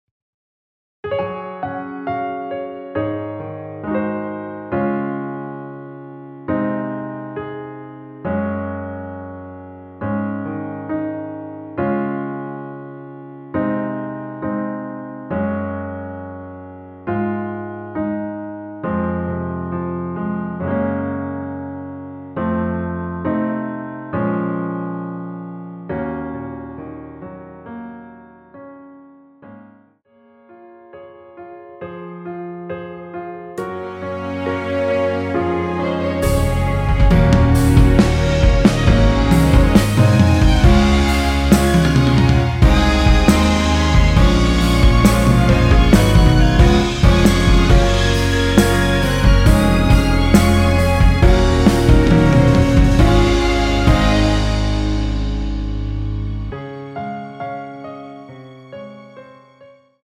원키에서(-2)내린 MR입니다.
Db
앞부분30초, 뒷부분30초씩 편집해서 올려 드리고 있습니다.
중간에 음이 끈어지고 다시 나오는 이유는